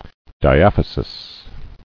[di·aph·y·sis]